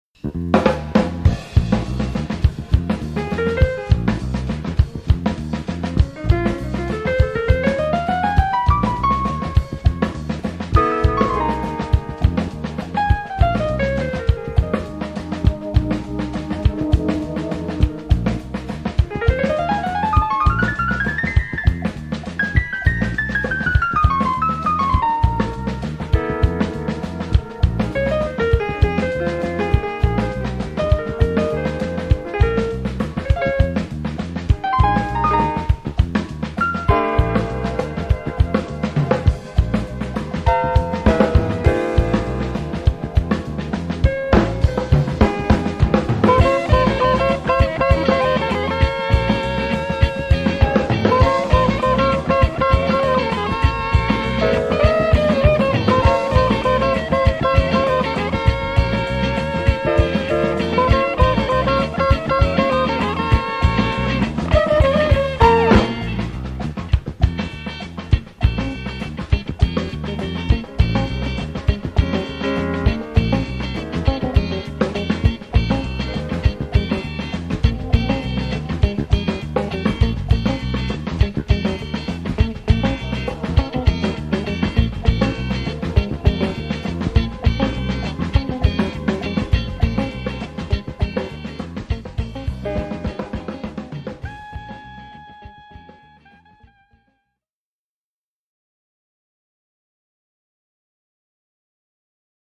electrifying and captivating musical feast.